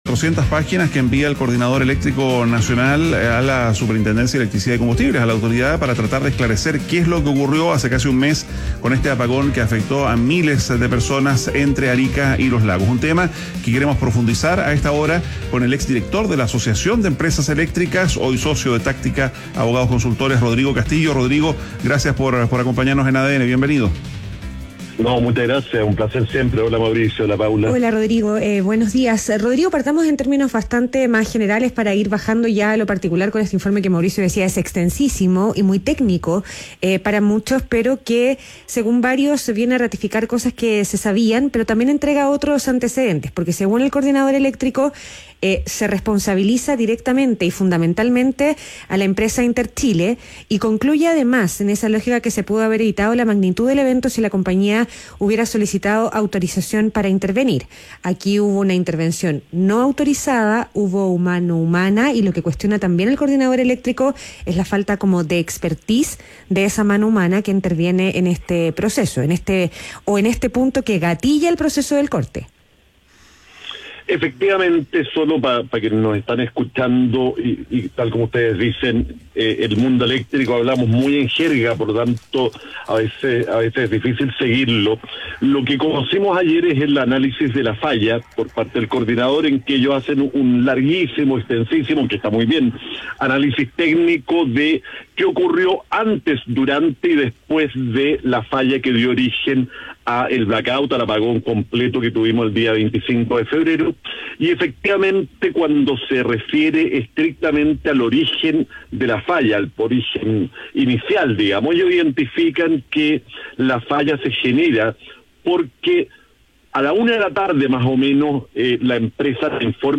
ADN Hoy - Entrevista